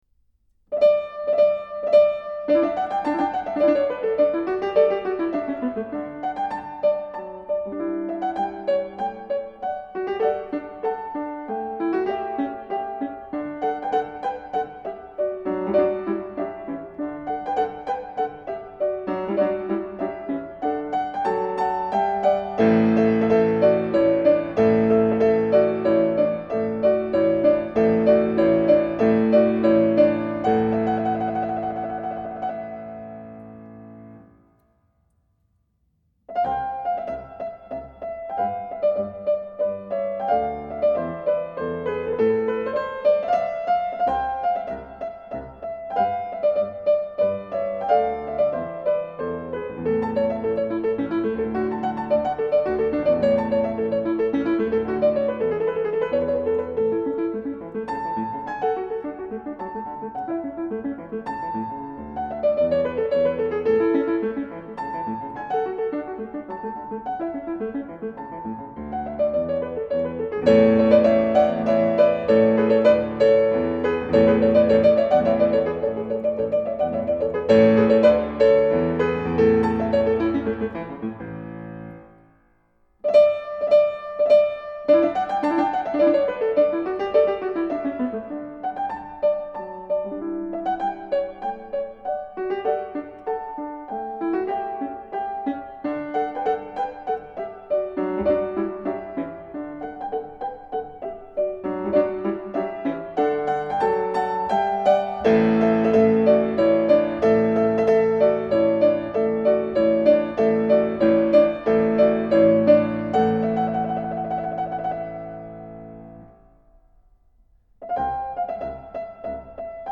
piano Bösendorfer.
Enregistré à Jesus-Kirche de Berlin en 2018.